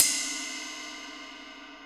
CUP RIDE 3.wav